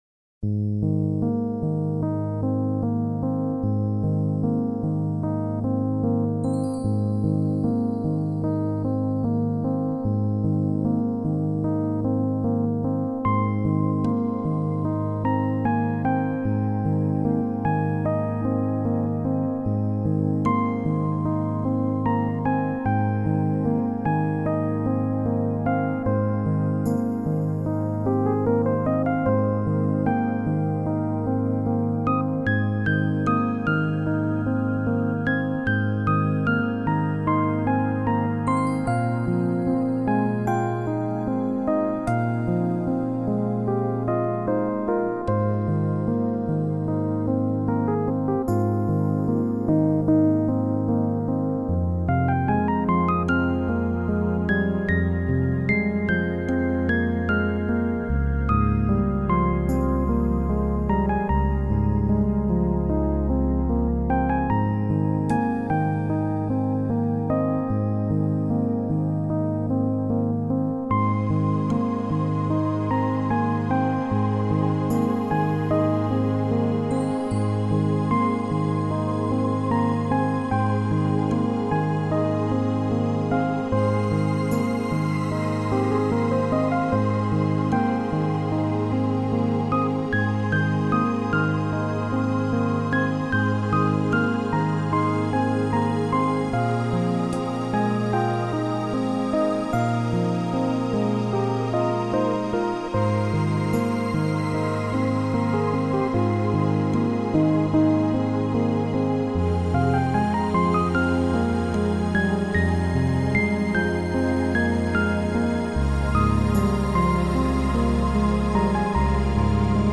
音乐流派: New Age, Healing